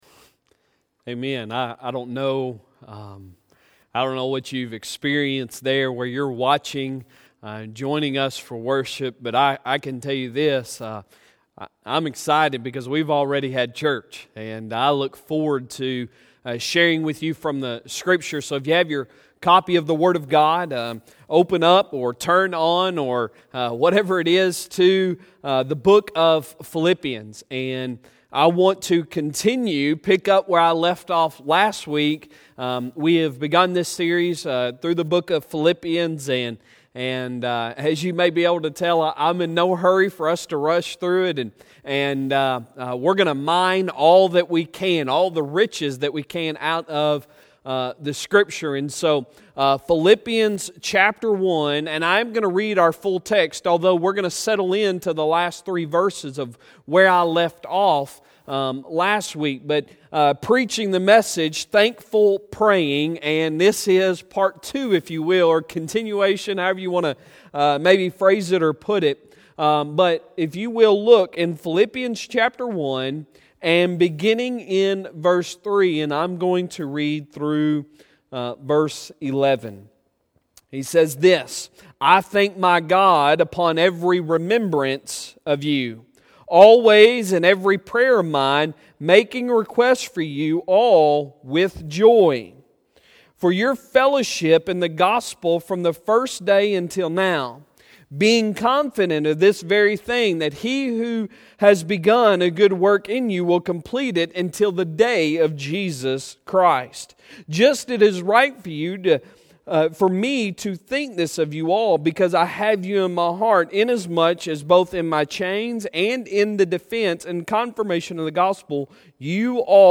Sunday Sermon May 10, 2020